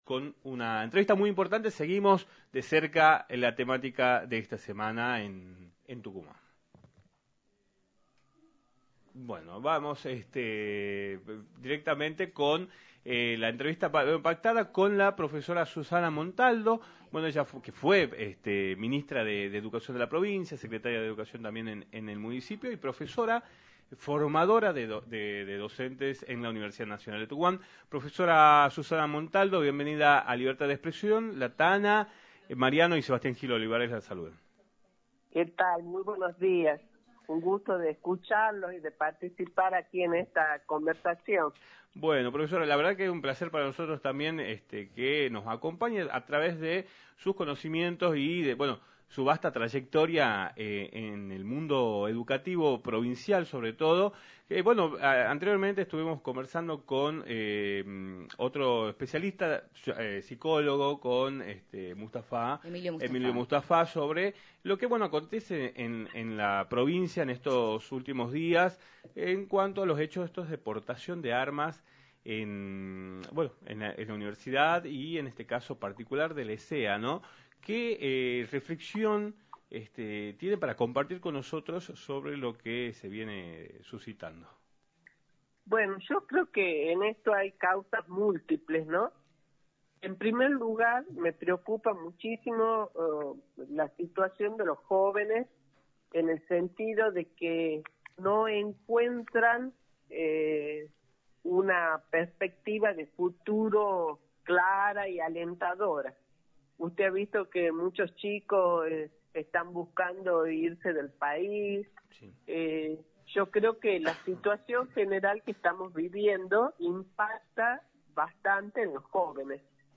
Susana Montaldo, Profesora, ex Ministra de Educación de la provincia y ex Secretaria de Educación de la Municipalidad, analizó en “Libertad de Expresión” por la 106.9, los hechos ocurridos de manera reciente, vinculados a estudiantes portadores de armas tanto en escuelas como en las universidades, hechos que causaron conmoción en la provincia.